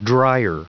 Prononciation du mot dryer en anglais (fichier audio)
Prononciation du mot : dryer